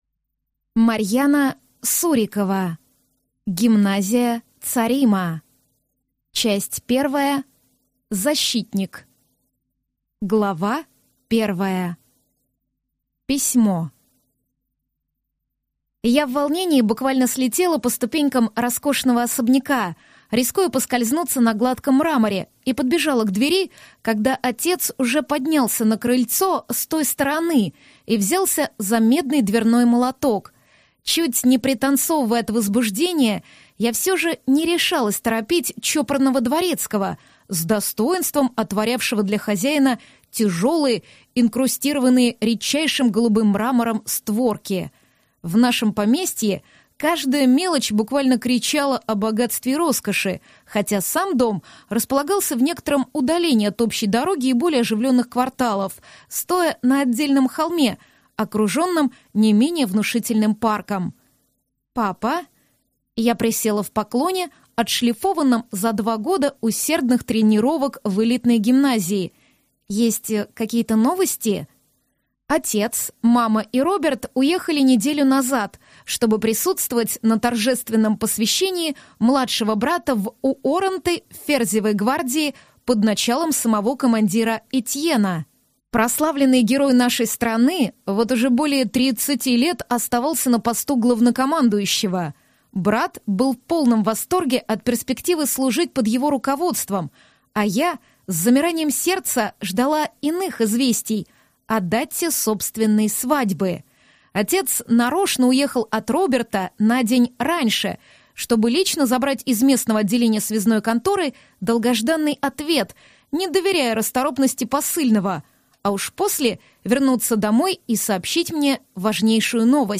Аудиокнига Гимназия Царима - купить, скачать и слушать онлайн | КнигоПоиск